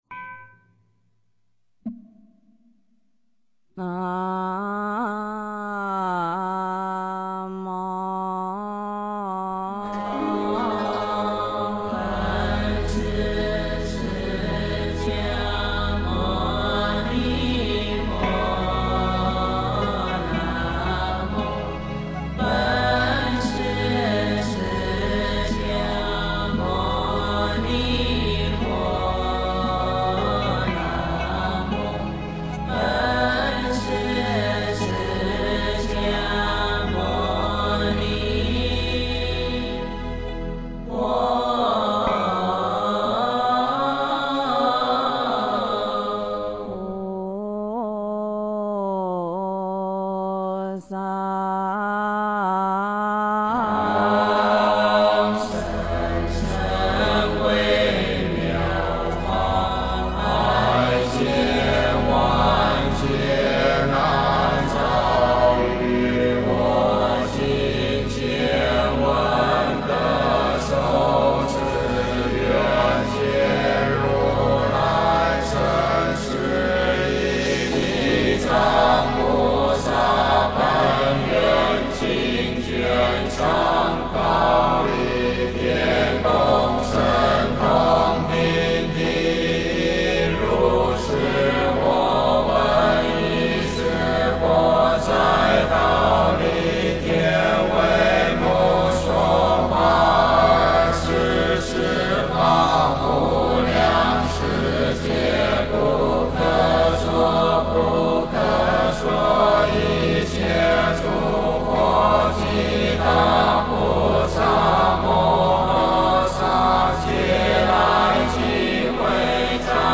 地藏经上卷 诵经 地藏经上卷--如是我闻 点我： 标签: 佛音 诵经 佛教音乐 返回列表 上一篇： 忏悔发愿文 下一篇： 佛宝赞 相关文章 般若波罗蜜多心经(达摩配乐精选) 般若波罗蜜多心经(达摩配乐精选)--佛教音乐...